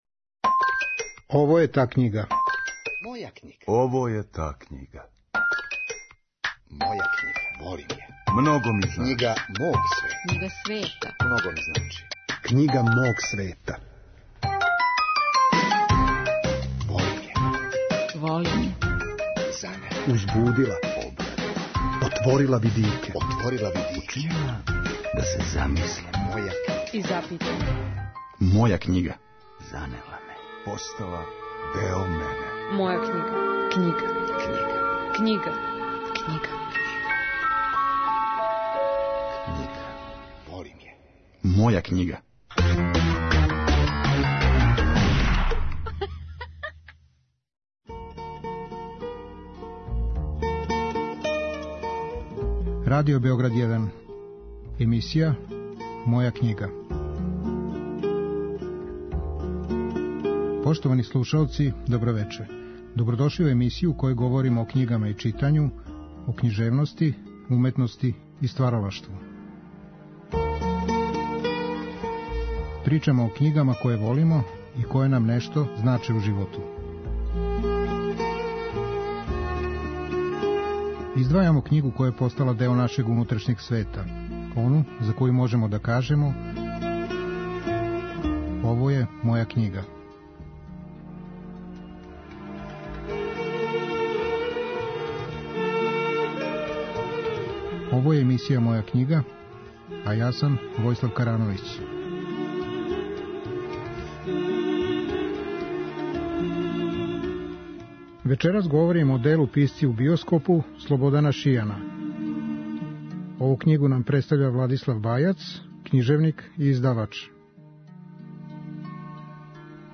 У вечерашњој емисији саговорник нам је Владислав Бајац, књижевник и издавач. Он говори о делу ''Писци у биоскопу'' нашег чувеног редитеља Слободана Шијана, који је, поред осталог, режирао култне филмове ''Ко то тамо пева'' и ''Маратонци трче почасни круг''. Ово дело не говори о филму, него о томе како су разни писци видели и доживели филм.